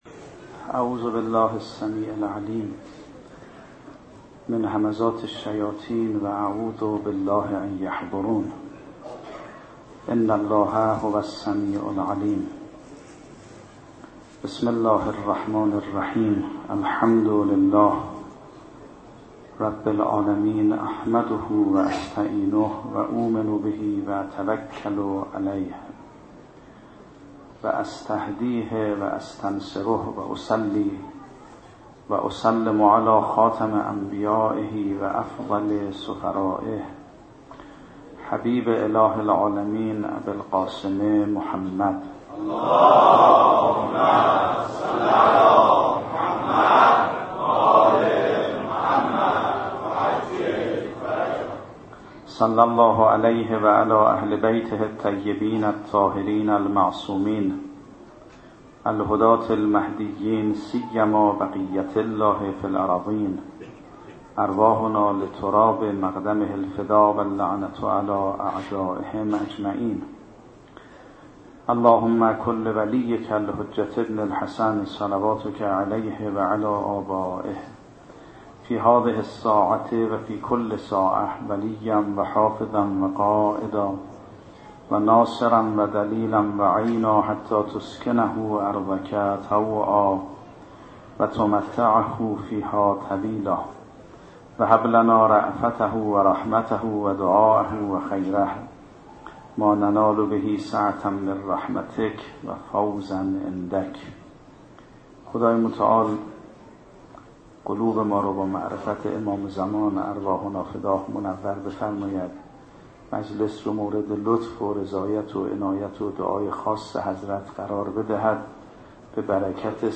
شب عاشورا محرم 96 - هیئت ثار الله - سخنرانی